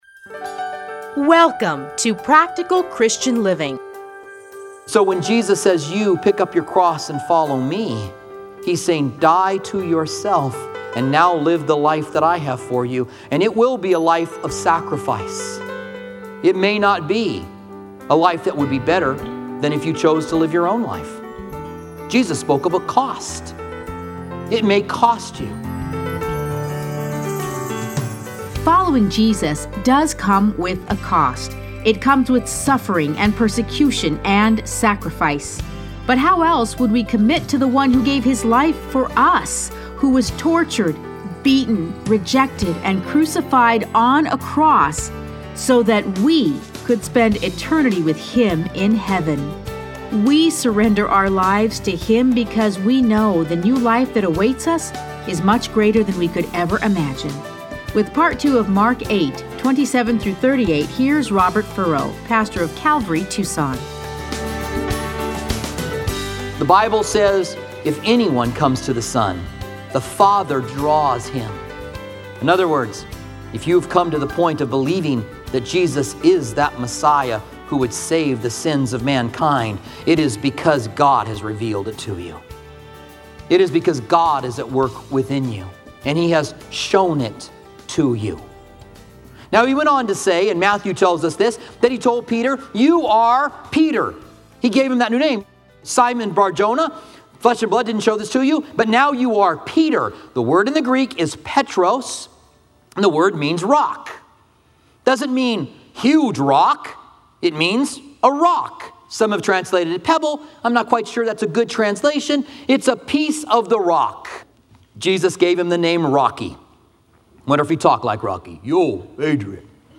Listen to a teaching from Mark 8:27-38.